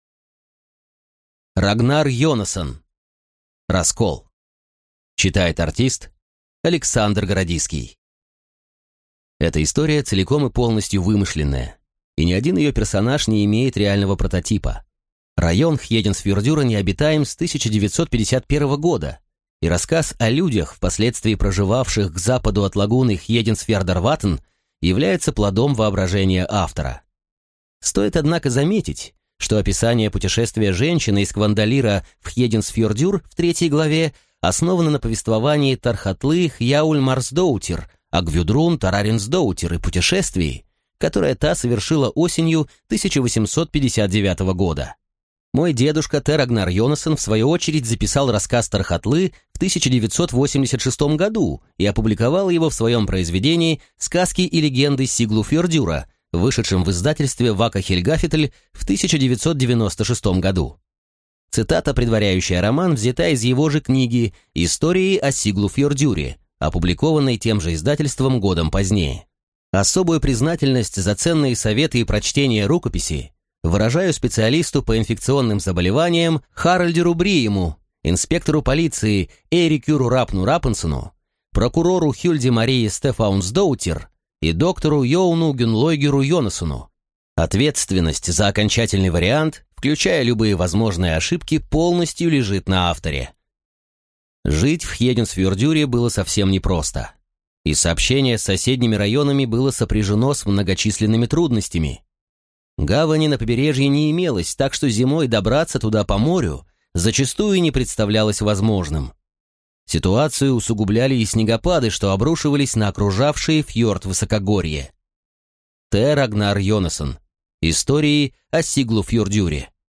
Аудиокнига Раскол | Библиотека аудиокниг